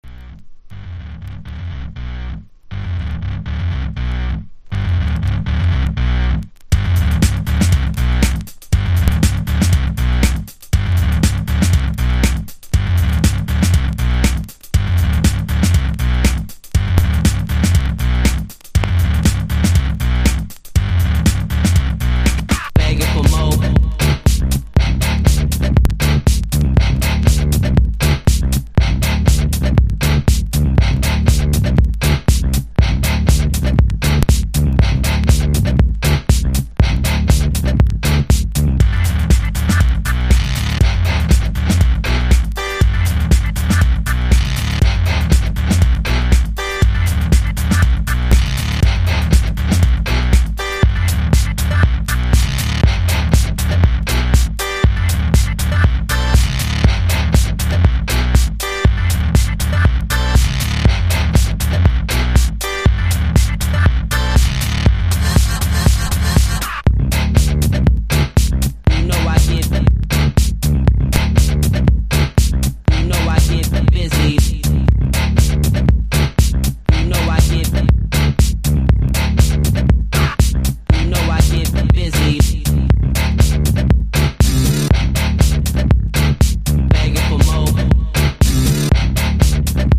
• HOUSE
パイプオルガンの音色が強烈なインパクトを放つフレンチ・エレクトロ・ハウス！！